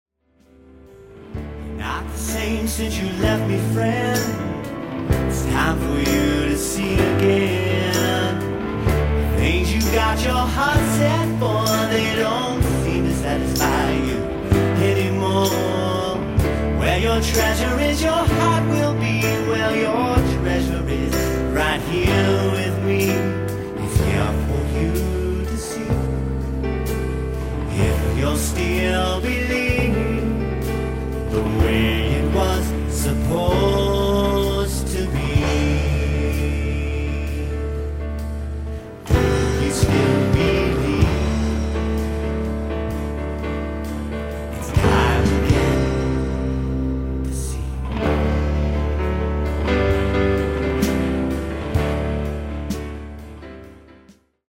Piano/Keyboards & Lead Vocals